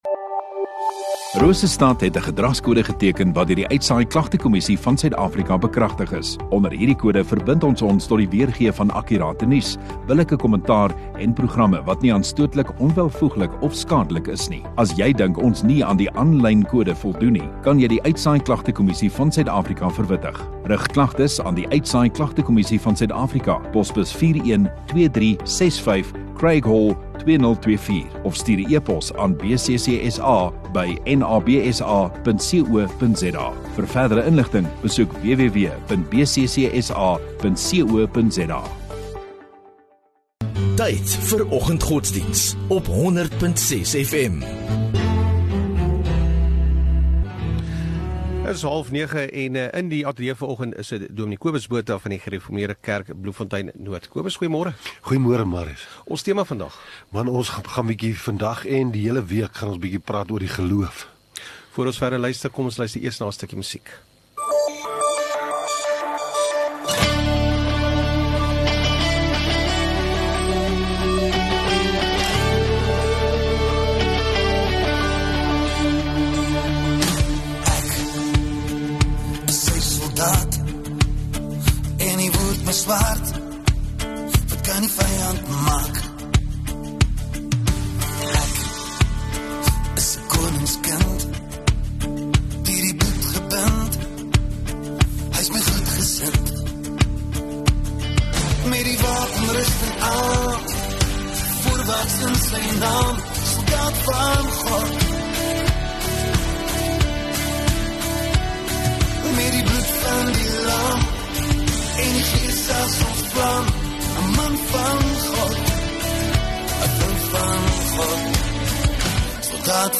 12 Aug Maandag Oggenddiens